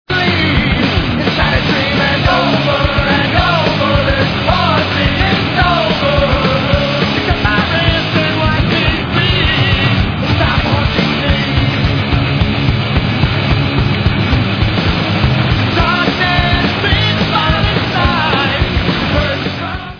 Rock/Hardcore